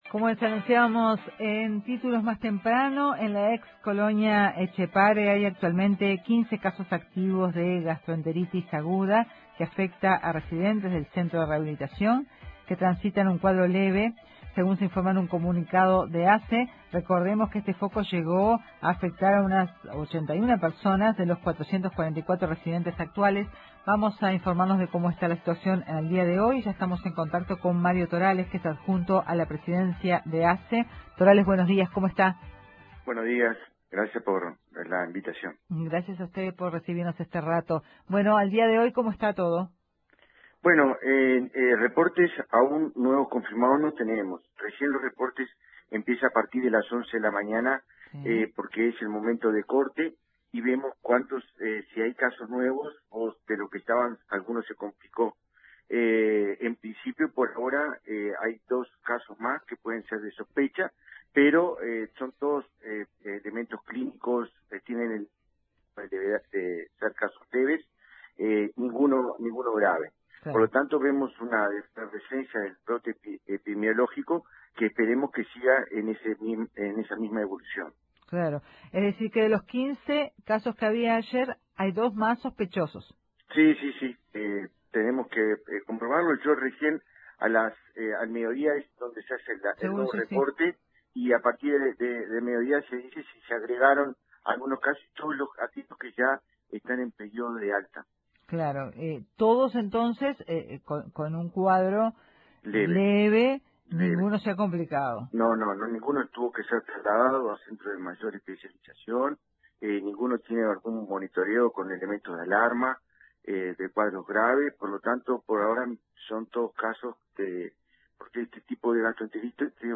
El adjunto a la presidencia de Asse, Mario Torales, dijo a Informativo Uruguay que los afectados todos los cuadros son leves.